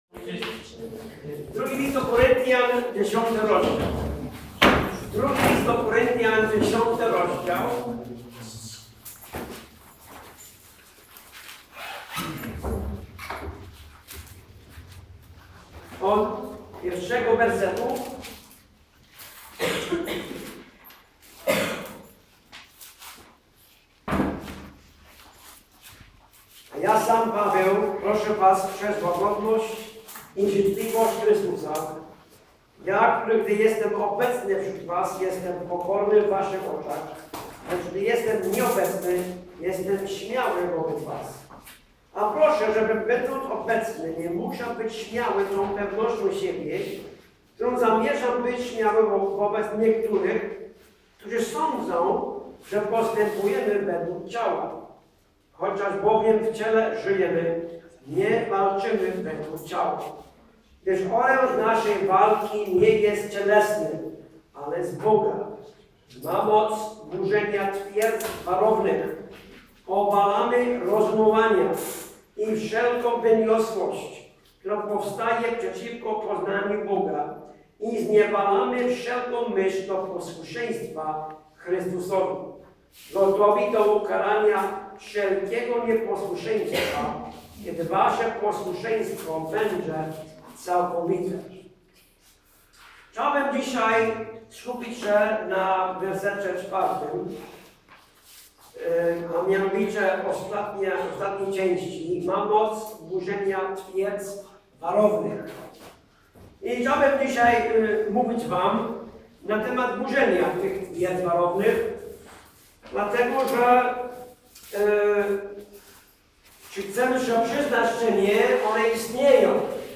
Kazanie
Wczasy Rodzinne w Oćwiece